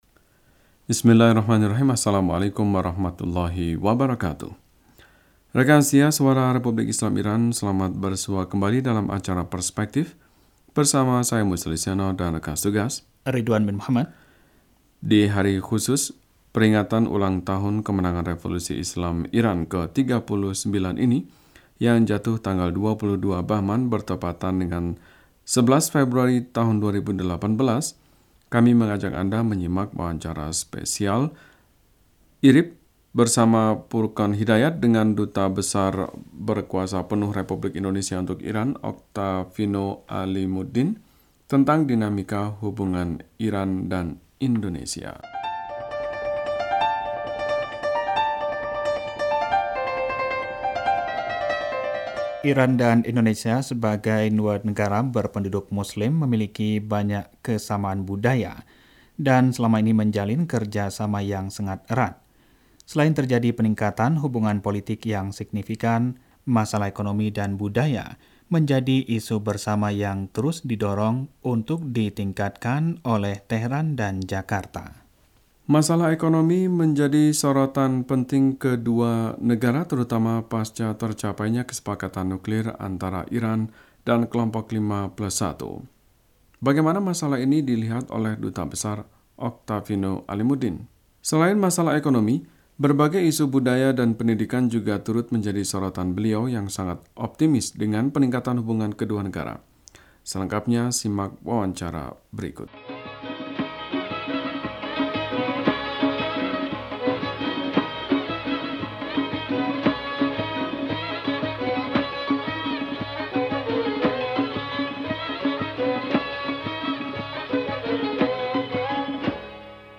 Wawancara: Iran di Mata Dubes Indonesia